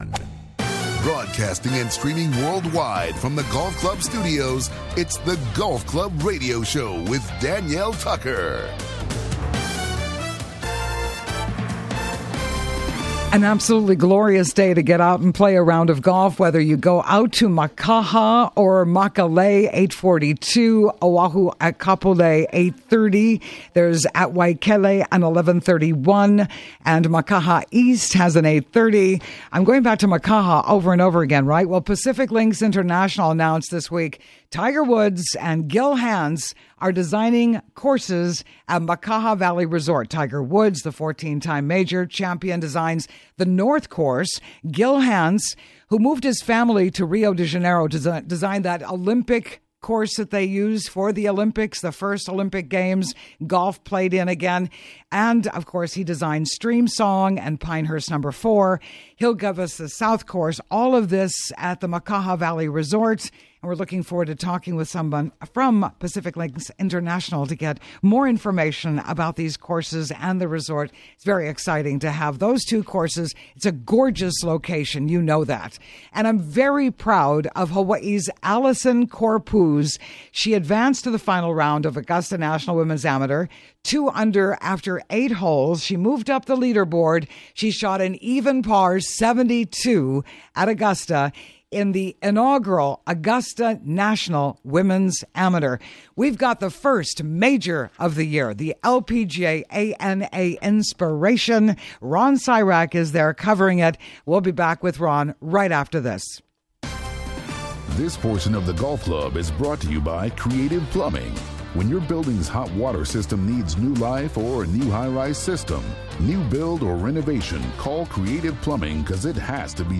Live SATURDAY MORNINGS: 7:00 AM - 8:30 AM HST